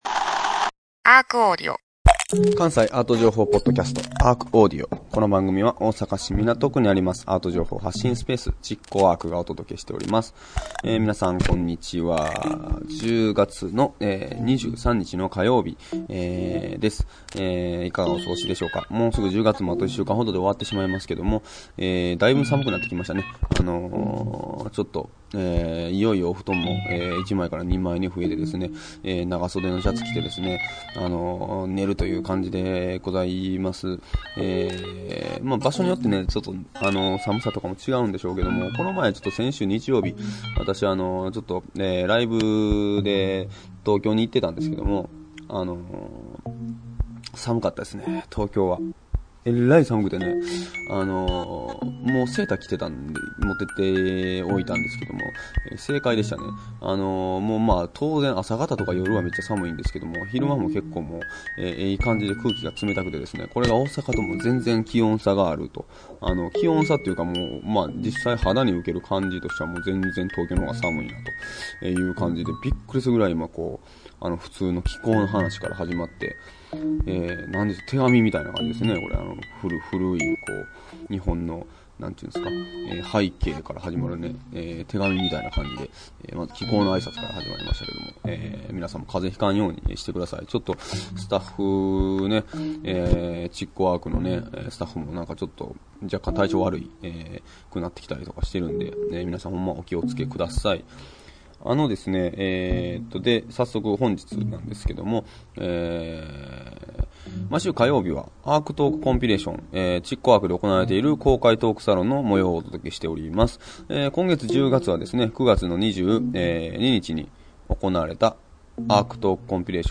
今回は会場からの質問を中心にお届けするシリーズ最終回です。